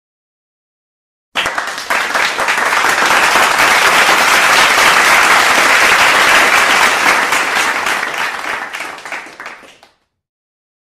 applause.mp3